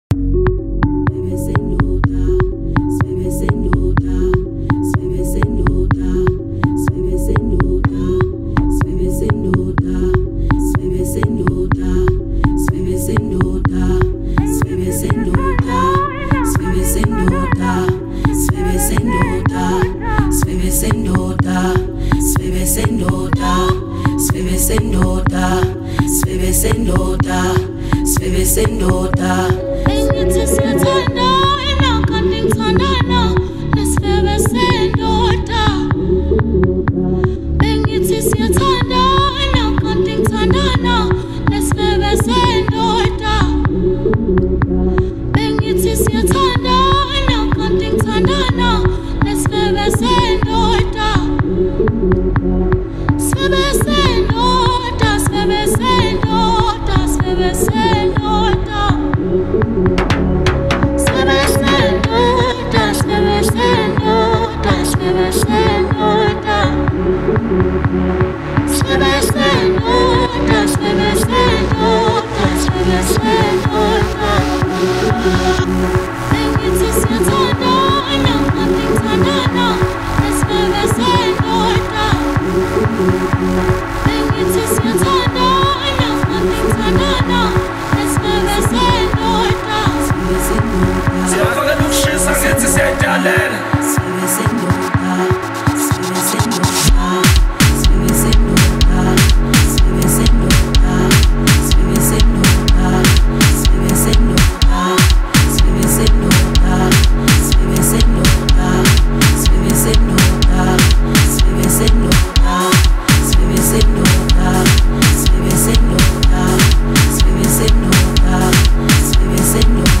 Home » Amapiano » DJ Mix » Hip Hop
is an elegantly arranged piece